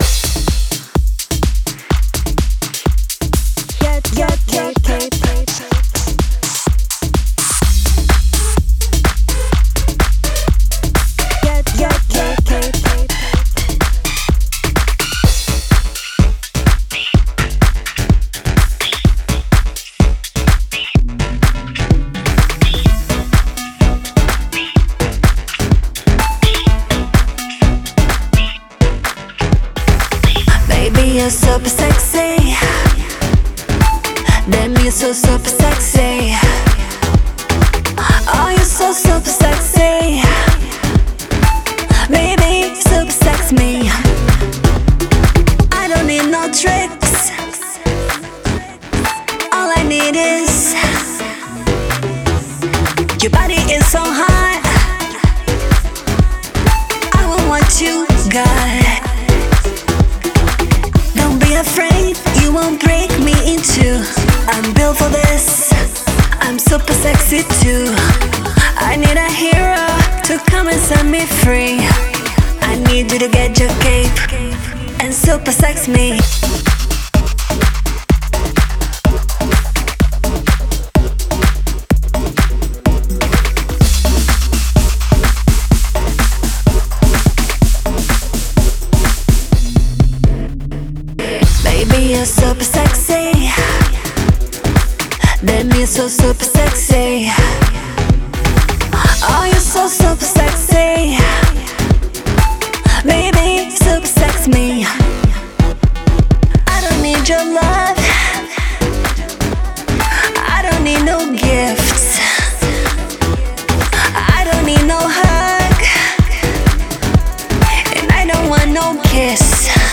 зажигательный трек в жанре хип-хоп и R&B